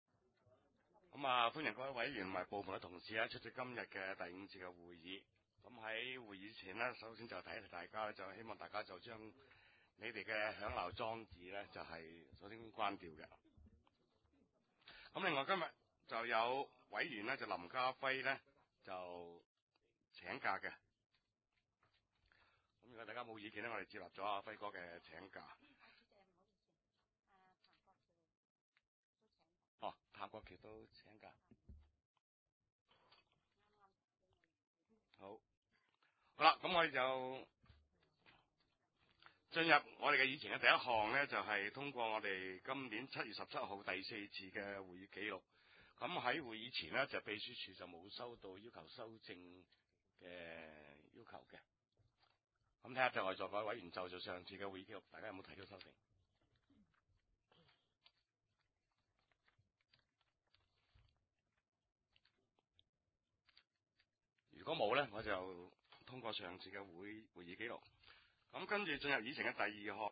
地點：深水埗區議會會議室